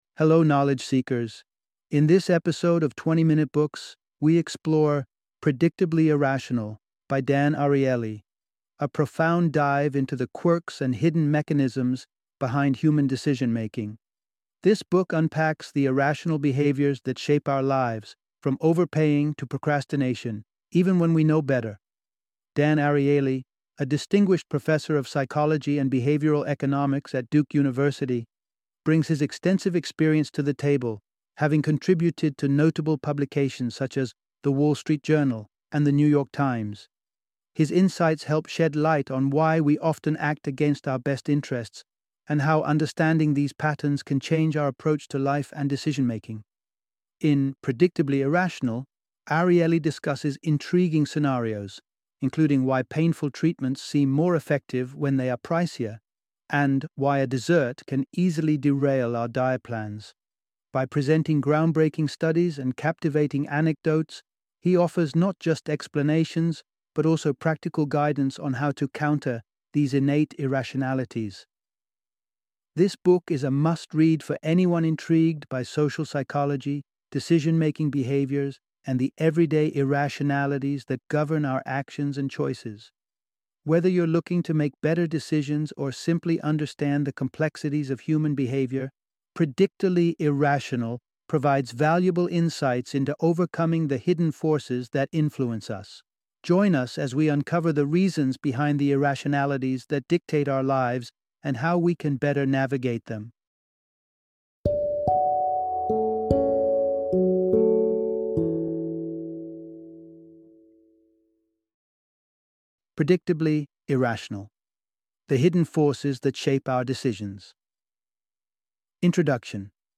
Predictably Irrational - Audiobook Summary